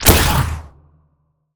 sci-fi_weapon_plasma_pistol_05.wav